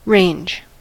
range: Wikimedia Commons US English Pronunciations
En-us-range.WAV